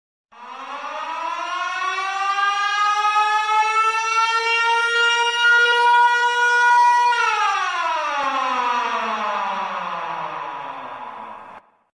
На этой странице собраны звуки сиреноголового — жуткие аудиозаписи, создающие атмосферу страха и неизвестности.
Сирена тревоги при авиаударе Сиреноголового